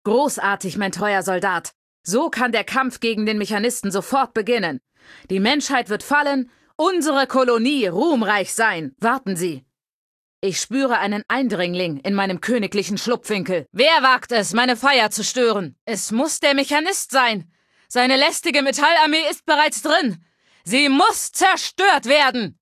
Datei:Femaleadult01default ms02 ms02superheroexplain3 0003c8c3.ogg
Fallout 3: Audiodialoge